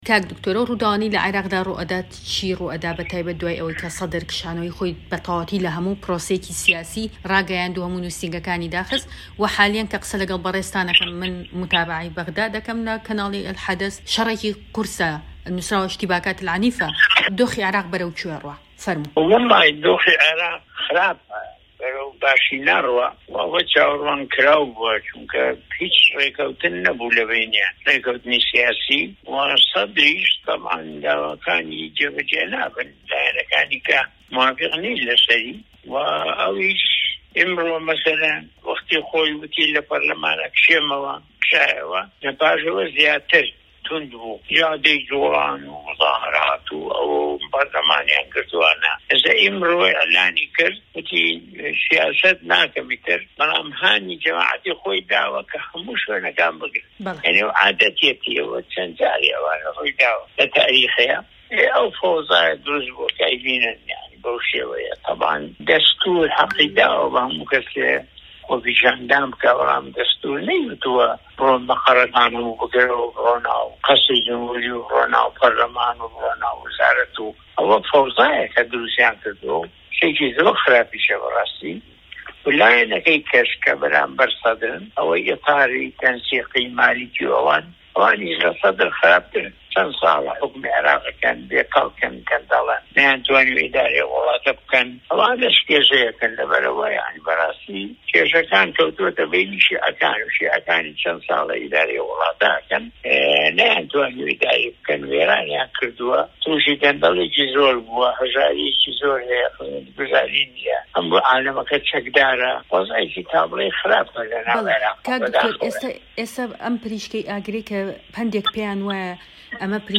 دەقی گفتوگۆ لەگەڵ د. مەحمود عوسمان